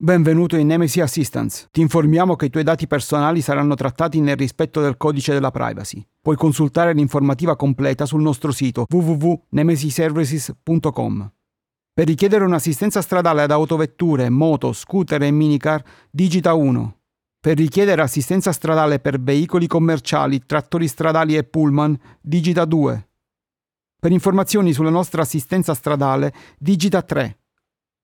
Italian speaker and voice artist, warm voice, young, middle, old, character voices, medical narration, e-learning, ads, commercial, audiobooks, IVR and phone system
Sprechprobe: Sonstiges (Muttersprache):
I have a warm voice and I can do a variety of voice delivery.
Usually I work with a CAD e100s condenser microphone, a Solid State Logic 2 audio interface but I have a tube amplifier if that kind of sound is needed.
IVR-ITA_3.mp3